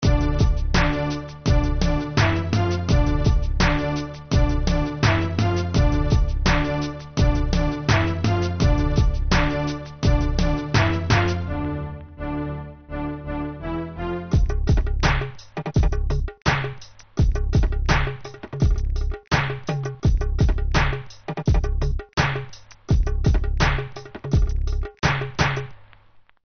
Минусовки (Hip-Hop)
Агрессивные:
2. (инструментальный); темп (84); продолжительность (3:20)